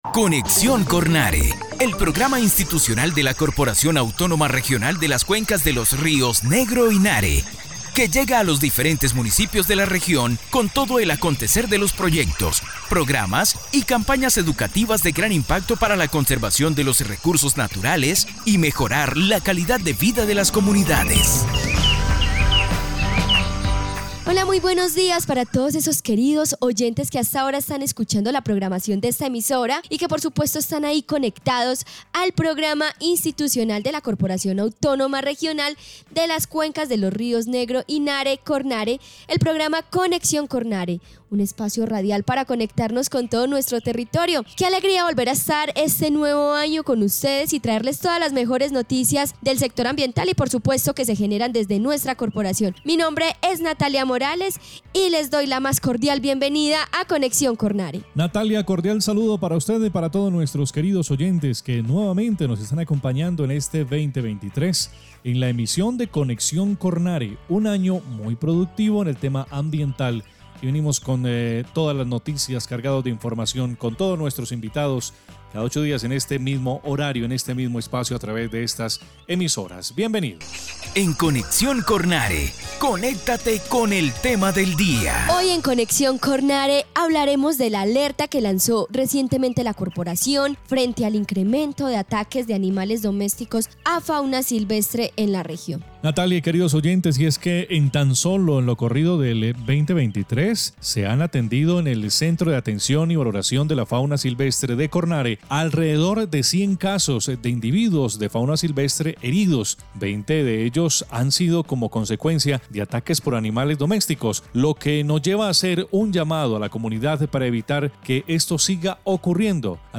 Programa de radio 2023